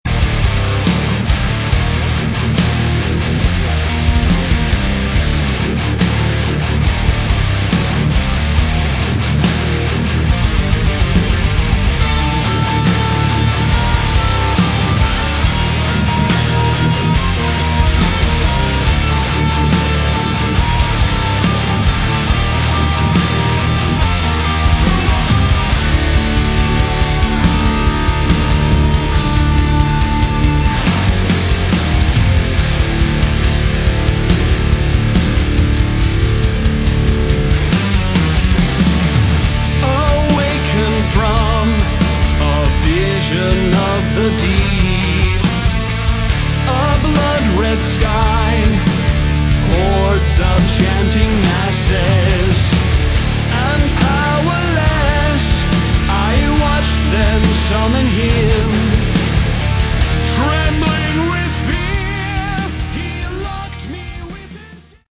Category: Rock
bass guitar, backing vocals
drums, backing vocals
guitar, backing vocals
lead vocals, backing vocals